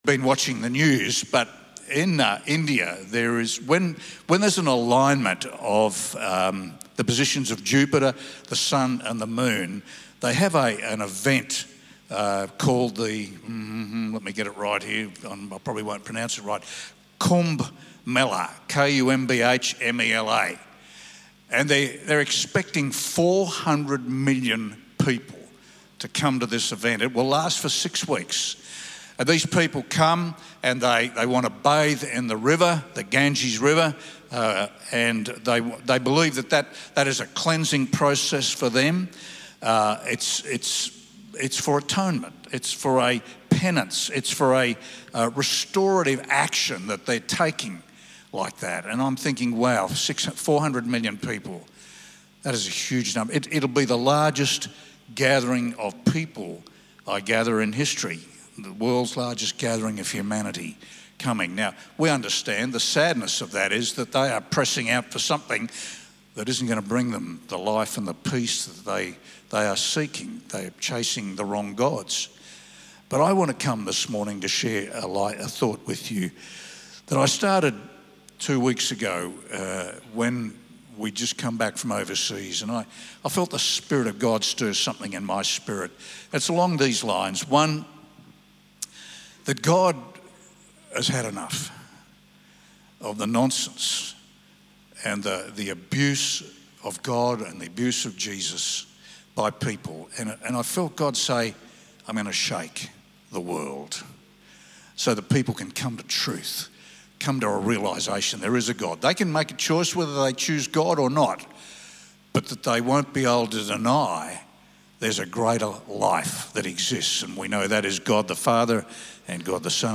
Sermons | Mackay Christian Family